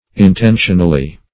Intentionally \In*ten"tion*al*ly\, adv.